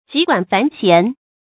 急管繁弦 注音： ㄐㄧˊ ㄍㄨㄢˇ ㄈㄢˊ ㄒㄧㄢˊ 讀音讀法： 意思解釋： 急：快；繁：雜。